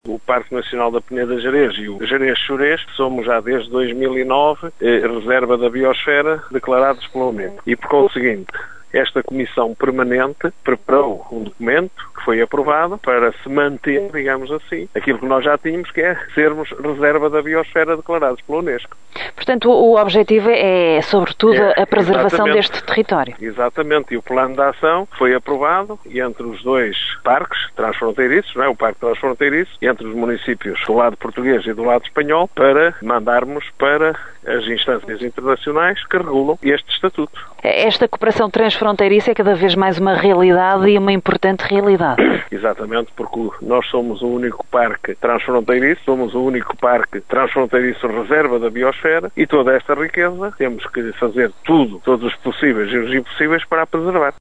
O presidente da Câmara de Ponte da Barca, um dos municípios do Parque Nacional da Peneda Gerês, sublinha o reforço da cooperação. Vassalo Abreu frisa que aquele território já era Reserva da Bioesfera da Unesco, uma riqueza que o autarca diz ser “fundamental” preservar.